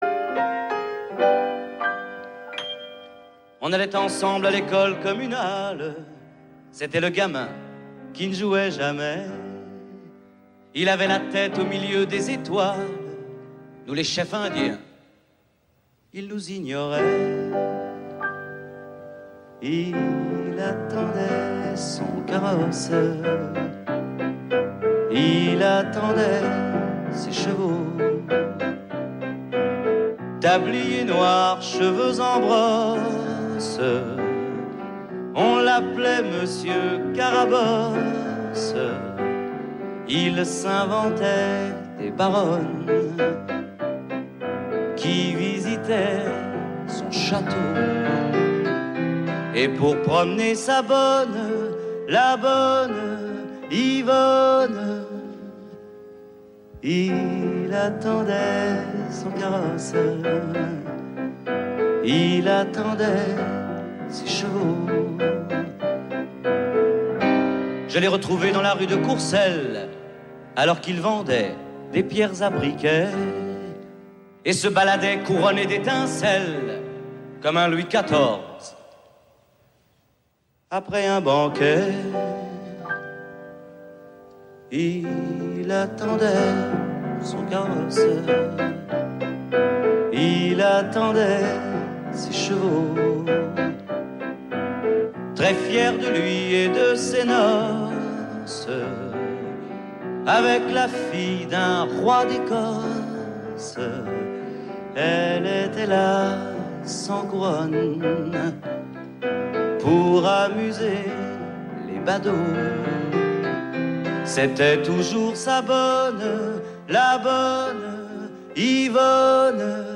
INEDITS SOLO TV/RADIO